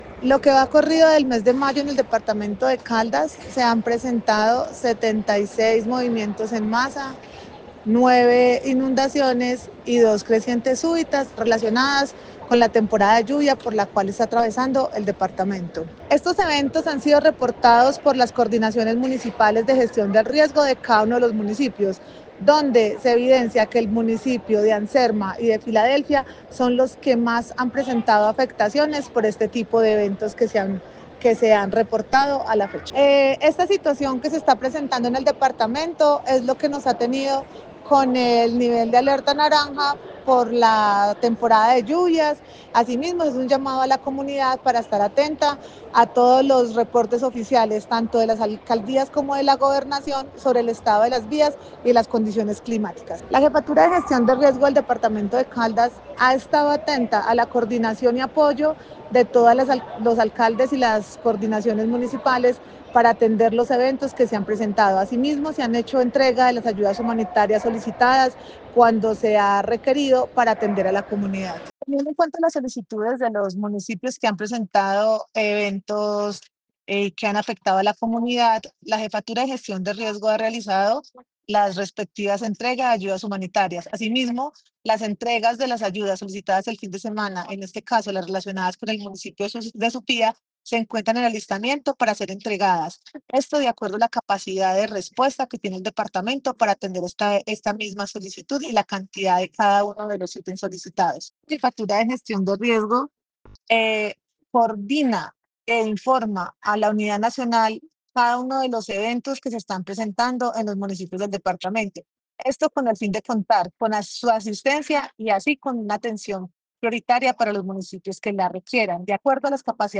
Paula Marcela Villamil Rendón, jefe de Gestión del Riesgo de Caldas
Testimonio-jefe-de-Gestion-del-Riesgo.mp3